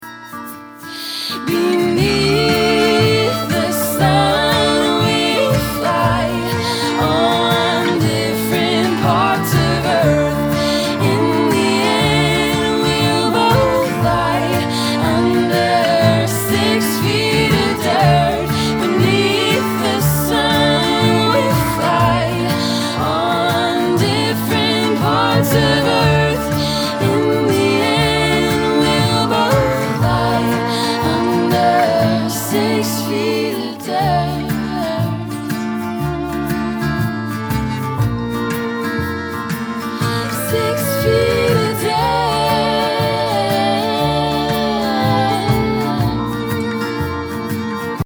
Here’s a snippet of the chorus I’ve got so far: